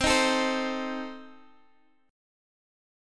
Listen to Cm6 strummed